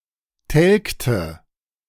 Telgte (German pronunciation: [ˈtɛlktə]